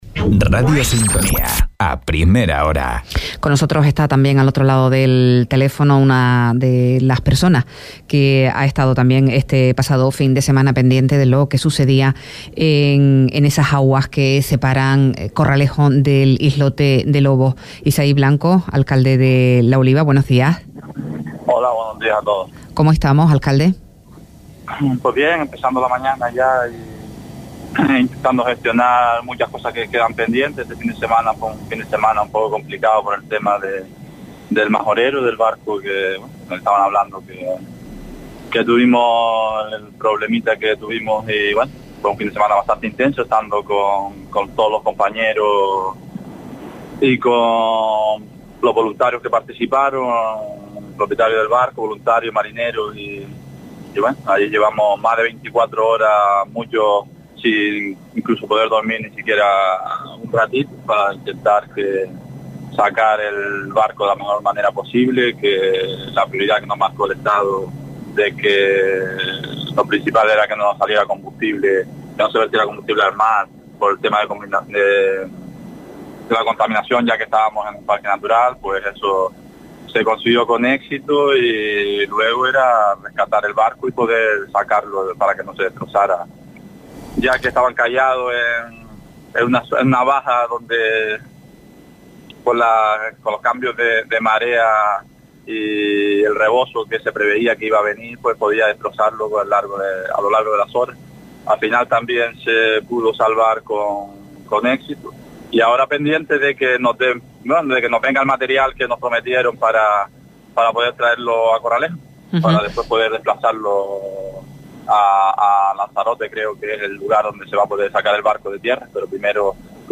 Isaí Blanco, alcalde del municipio de La Oliva explicó hoy que está pendiente del traslado del 'Majorero' al puerto de Corralejo
Entrevistas